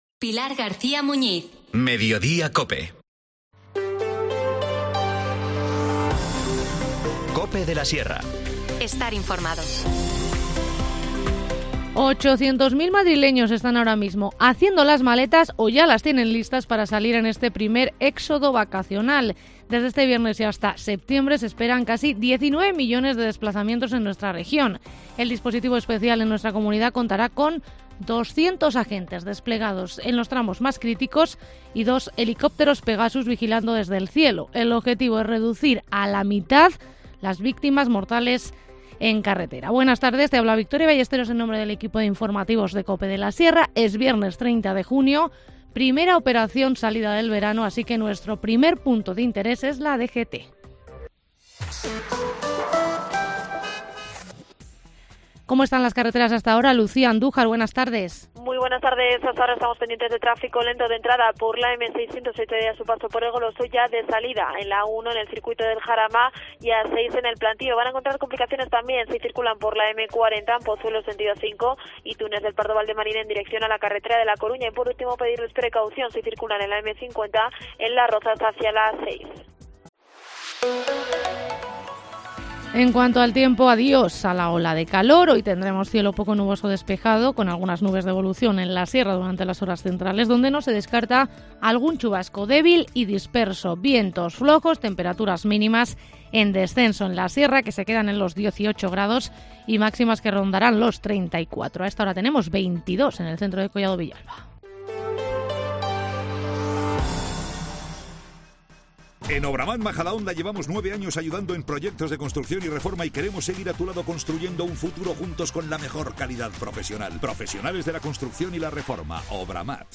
Informativo Mediodía 30 junio